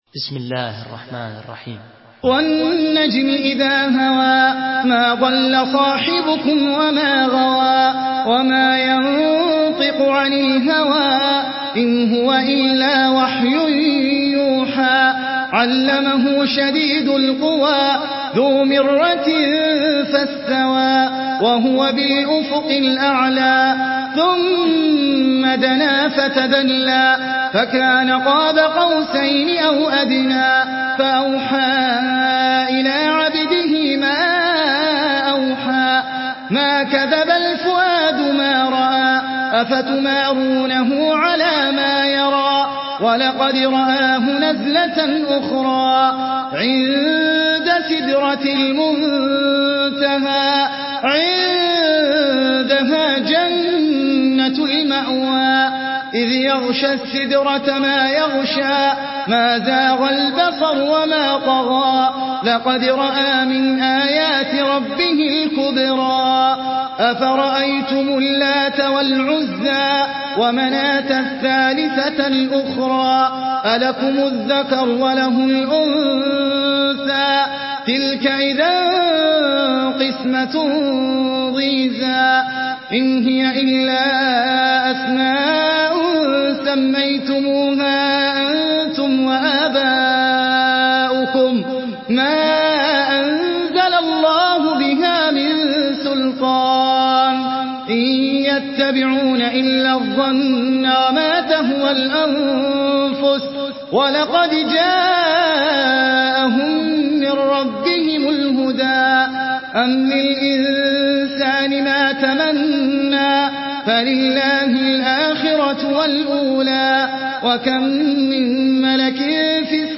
Surah আন-নাজম MP3 by Ahmed Al Ajmi in Hafs An Asim narration.
Murattal Hafs An Asim